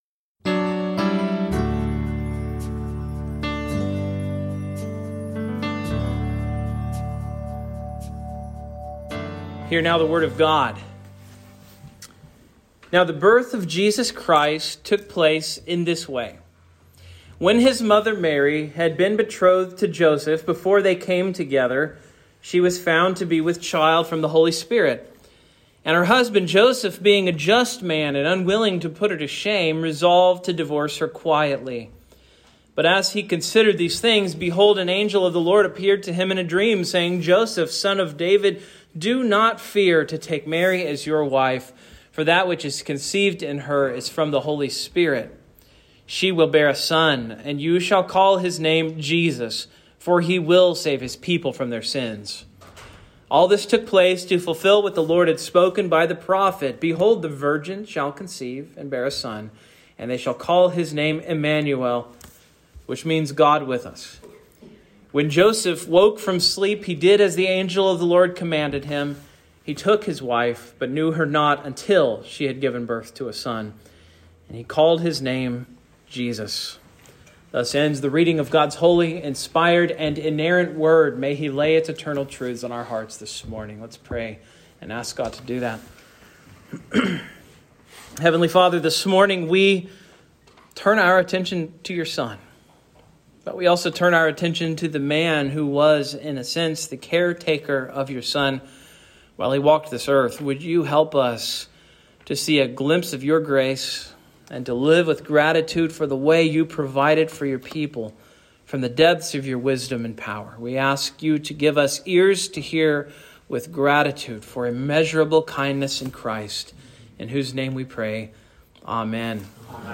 Son of Joseph - Evergreen Presbyterian Church